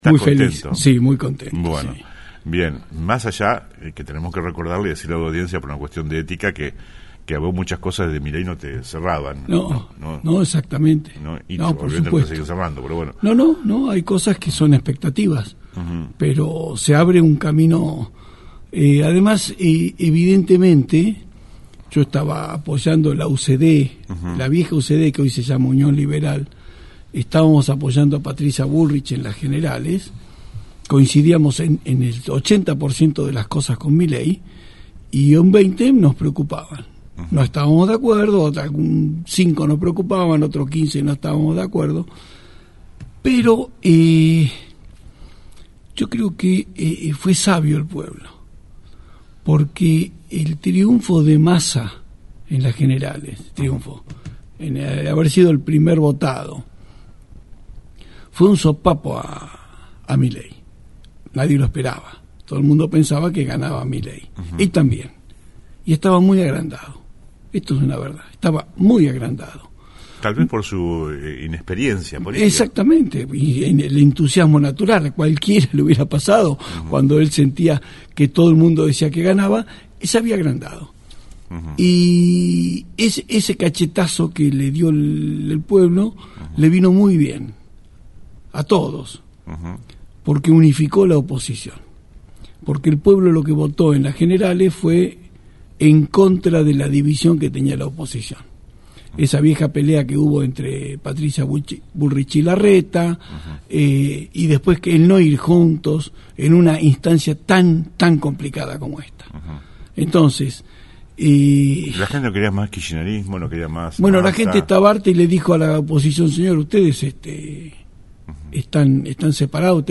Después del contundente triunfo de Javier Milei a nivel local y nacional, invitamos al librepensador y ex legislador provincial Fernando Cámara.